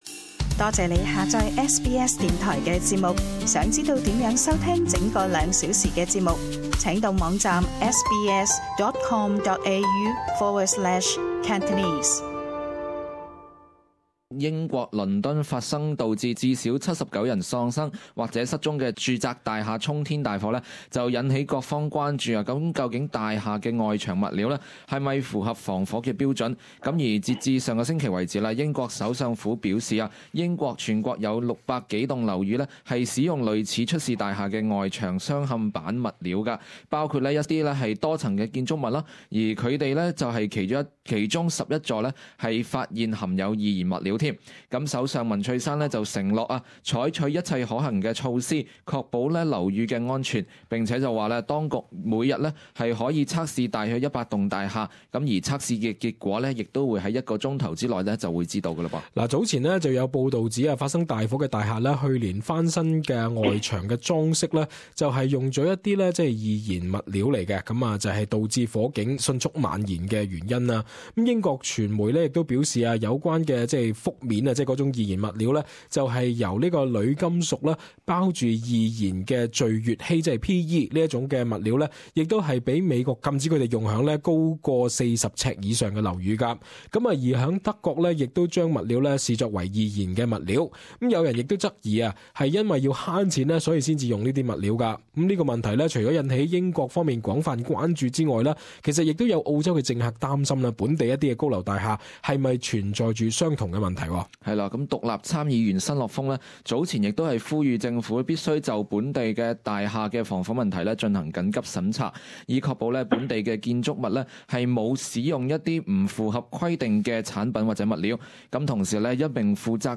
與聽眾們討論這個話題。